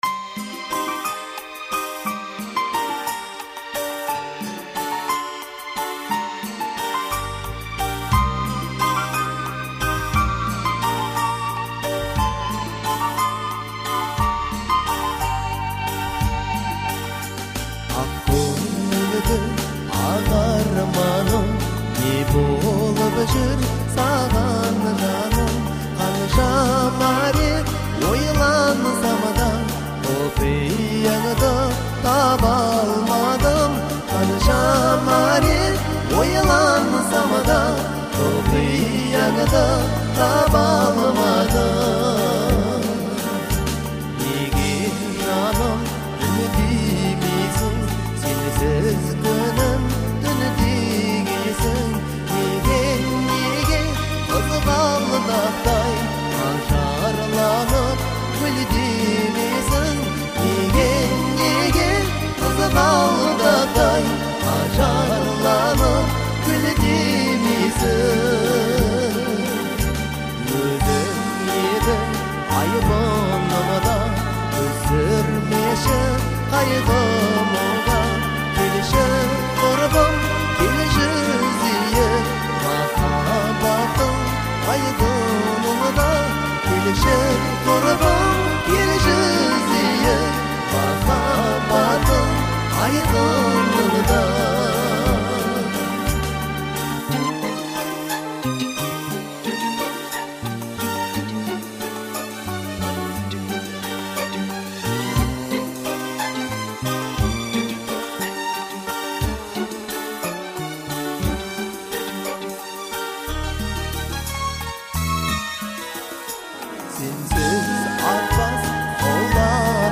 казахской народной музыки
наполнена меланхолией и глубокой эмоциональностью